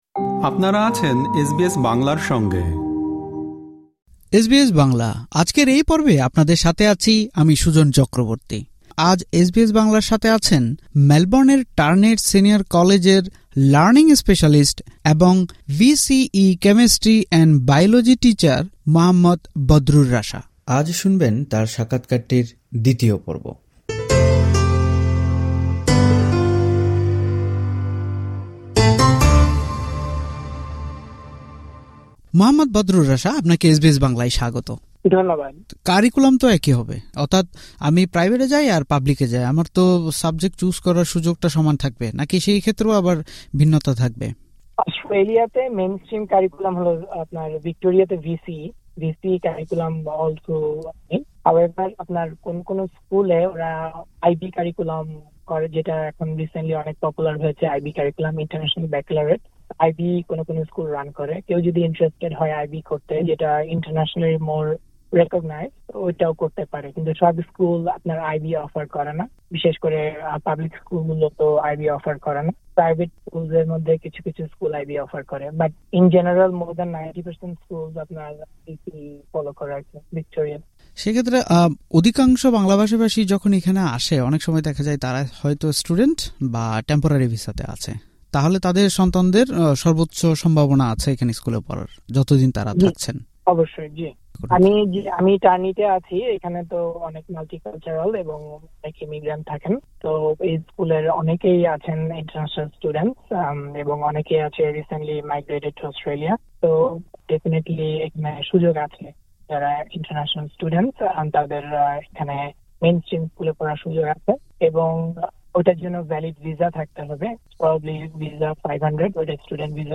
দুই পর্বের এই সাক্ষাৎকারের শেষ অংশে থাকছে— বাংলা মাধ্যম থেকে এসে এখানে কী ধরনের অসুবিধা হতে পারে, বিষয় বেছে নেওয়ার জন্যে কী মাথায় রাখতে হবে, স্কুলের ফিস সাধারণত কেমন হয় এবং কীসের উপর নির্ভর—এরকম সব গুরুত্বপূর্ণ আলোচনা।